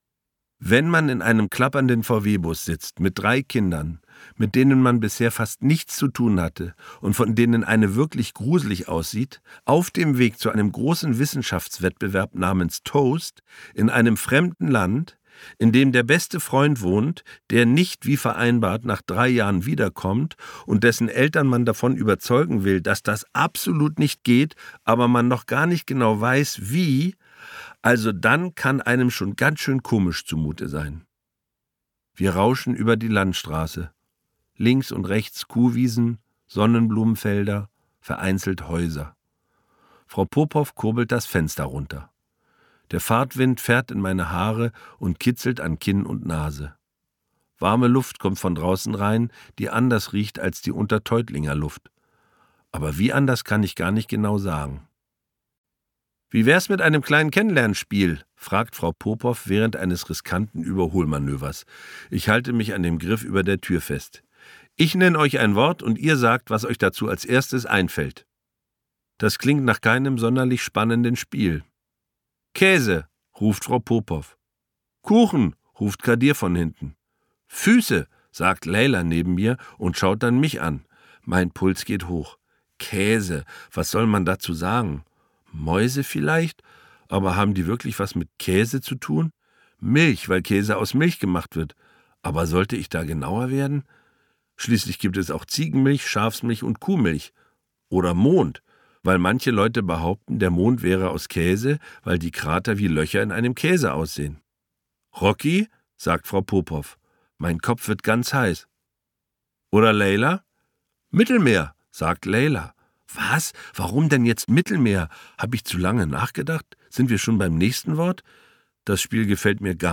Hörbuch
Sprecher Charly Hübner